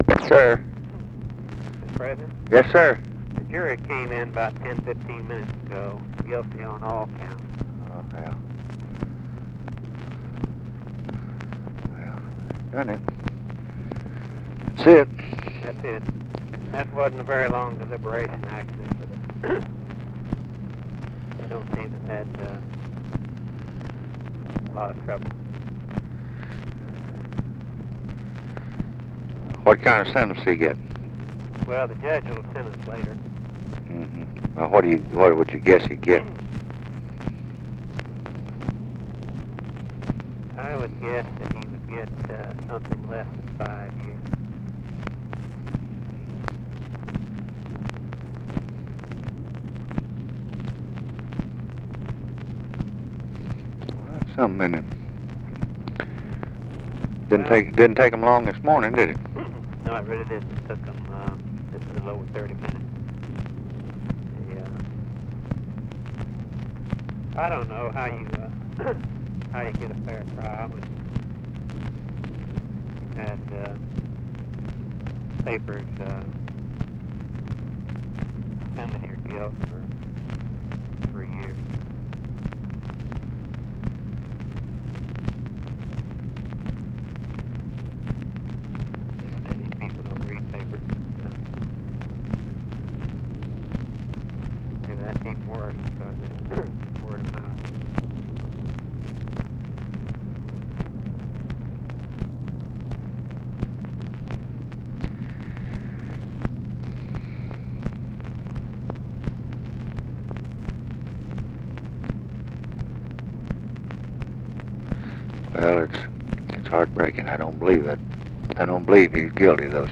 Conversation with RAMSEY CLARK, January 29, 1967
Secret White House Tapes